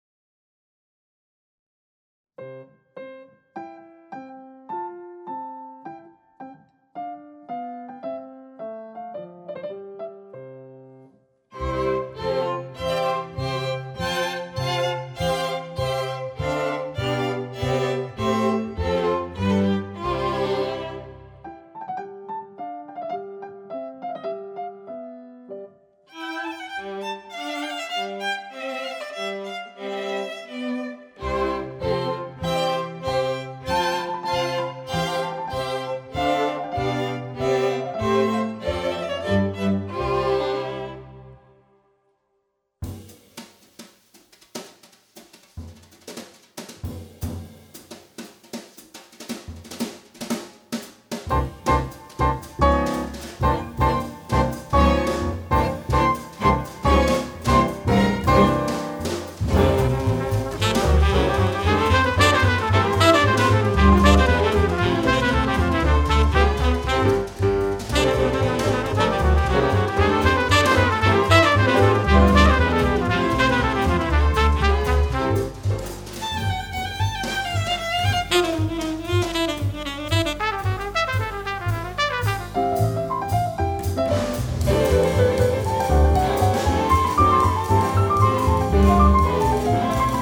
klavir
kontrabas
trobenta
bobni